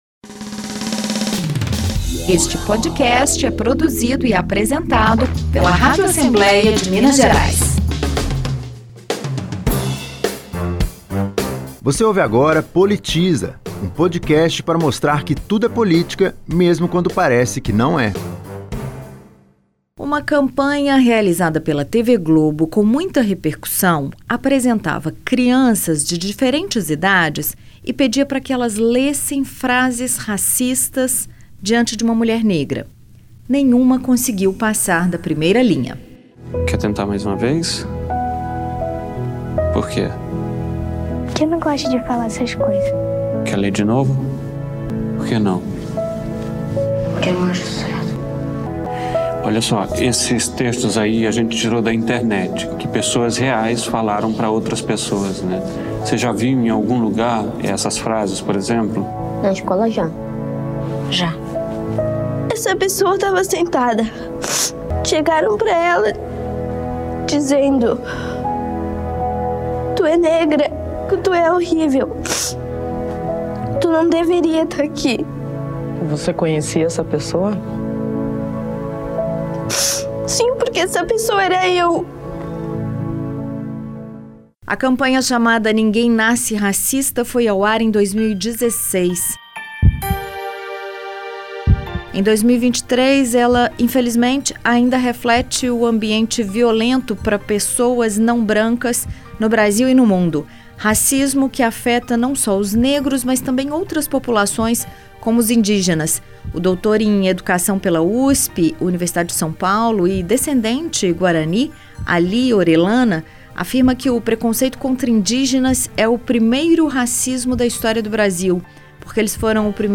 Entrevistada